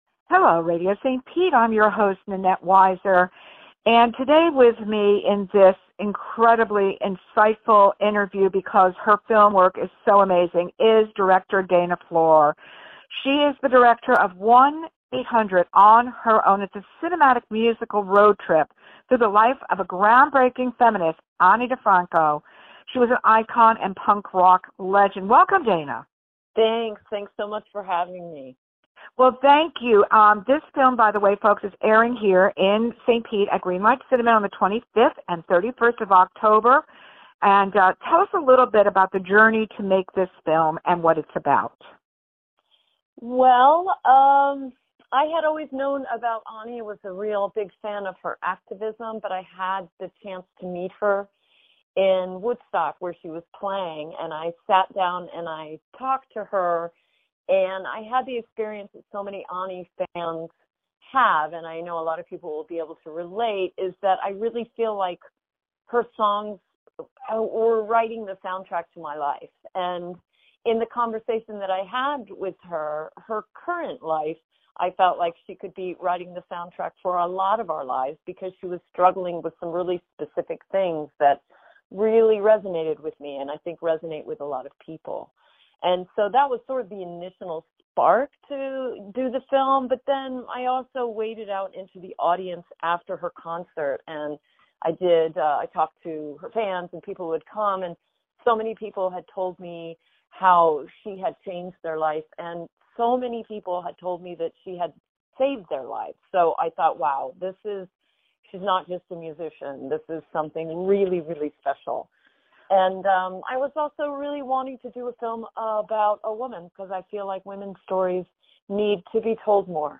FILM: Interview